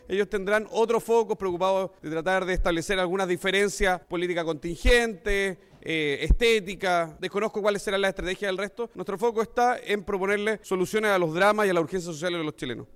Tras estas declaraciones, el jefe de bancada del Partido Republicano, Cristian Araya, declaró a La Radio que el foco es vencer al crimen organizado, crecer y no atacar a quienes están en la oposición. El diputado sostuvo, además, que Kast no entrará en esta disputa.